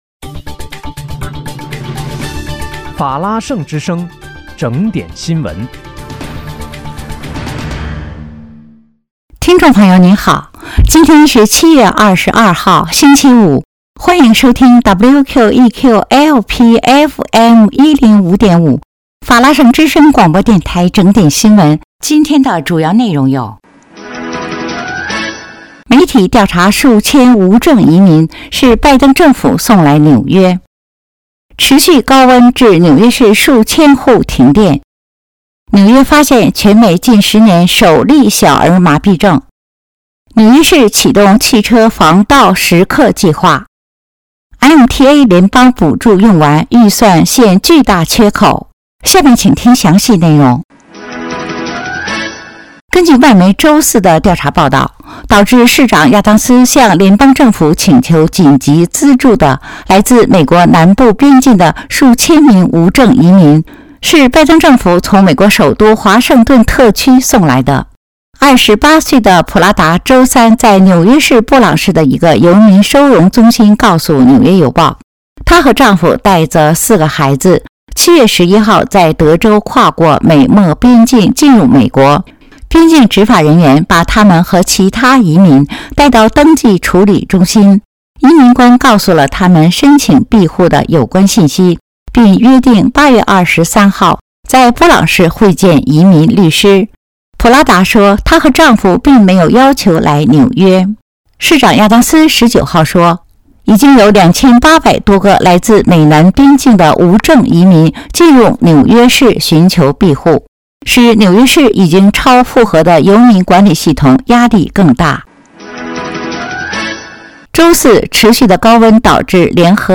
7月22日（星期五）纽约整点新闻